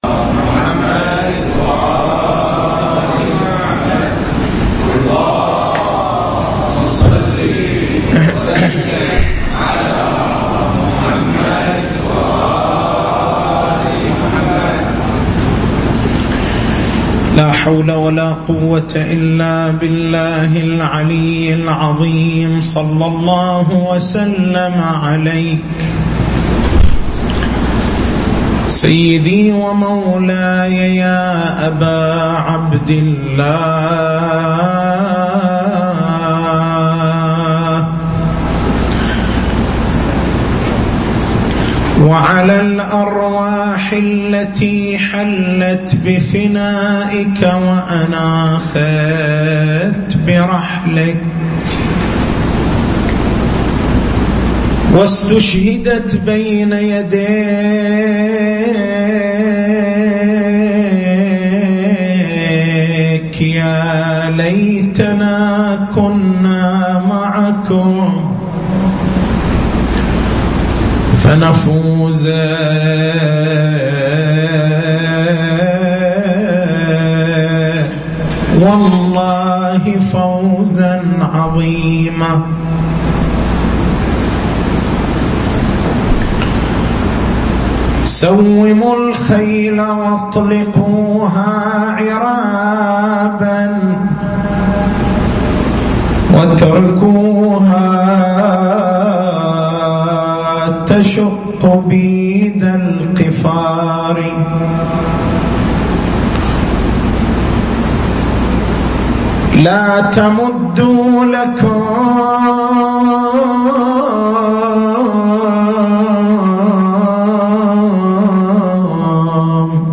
تاريخ المحاضرة: 08/09/1435